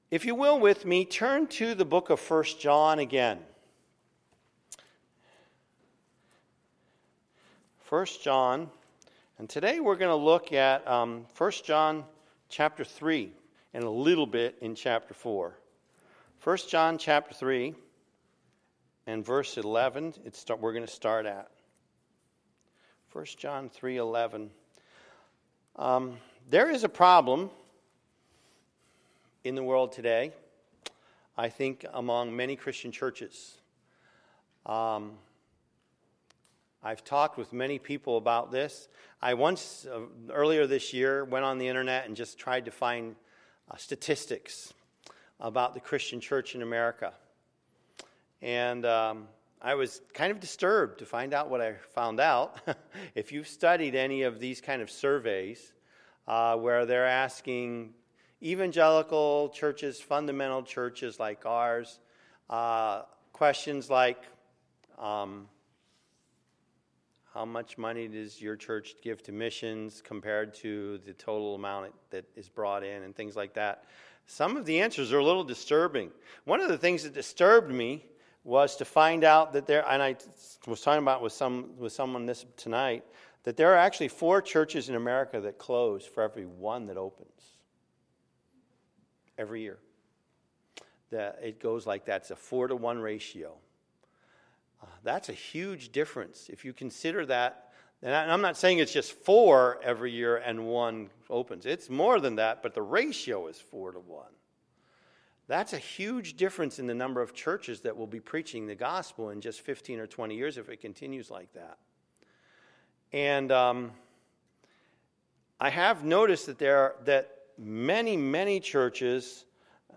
Wednesday, September 28, 2016 – 2016 Missions Conference – Wednesday Evening Session
Sermons